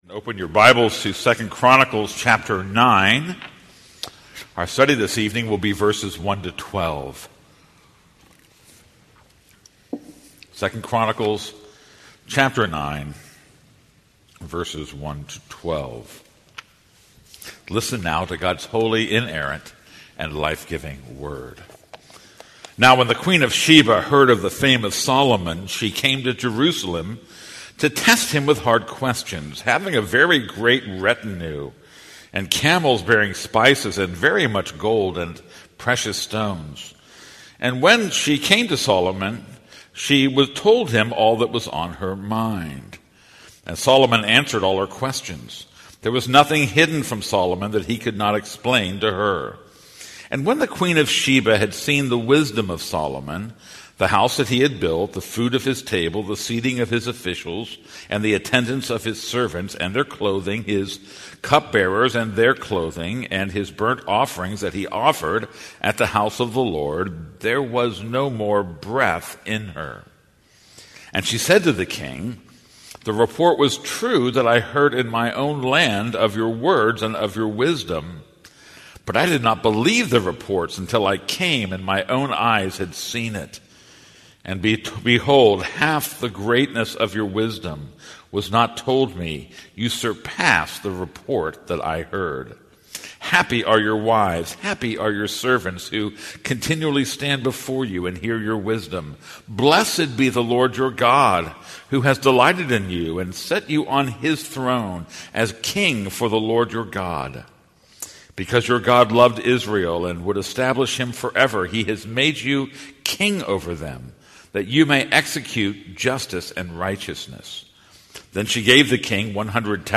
This is a sermon on 2 Chronicles 9:1-12.